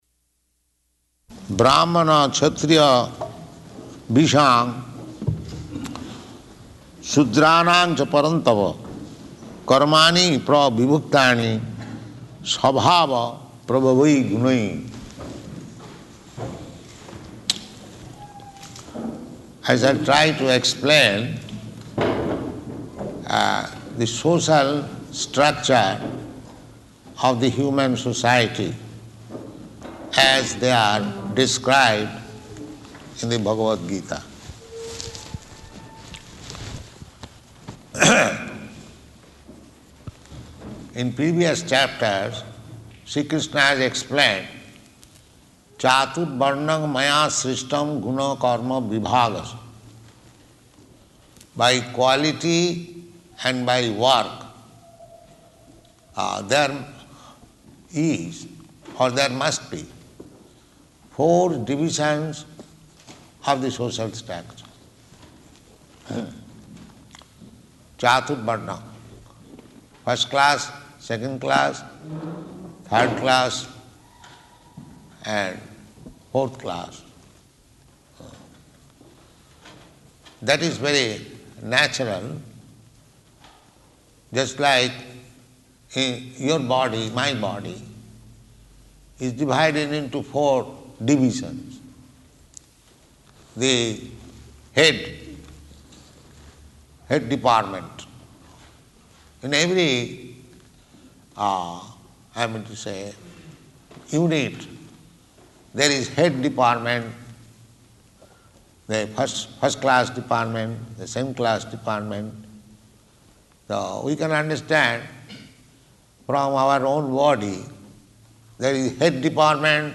Location: Stockholm